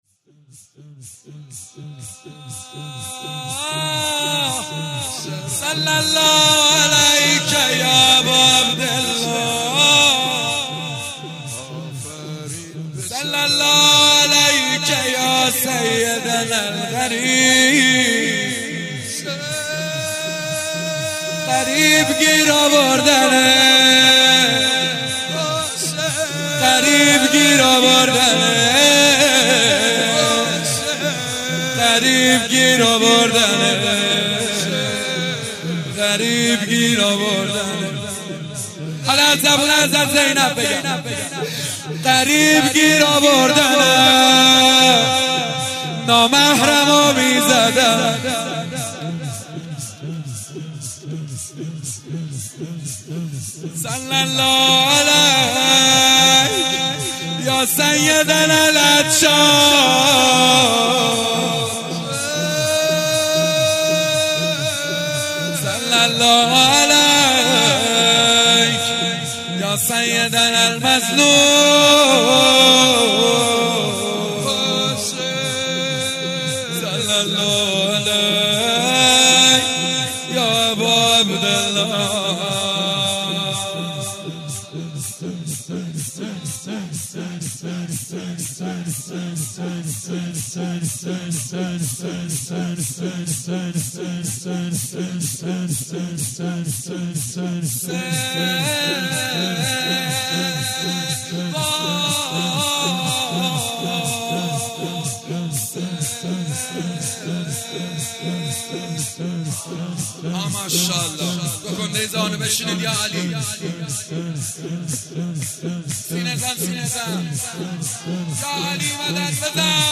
هئیت رزمندگان غرب تهران/رمضان96